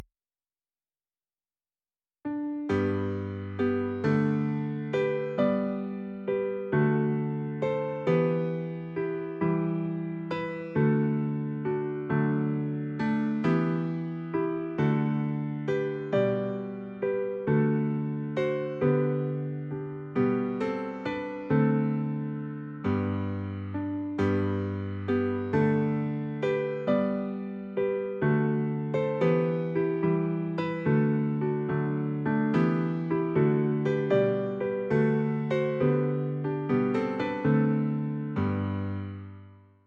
musique de Noël